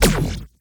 etfx_shoot_energy03.wav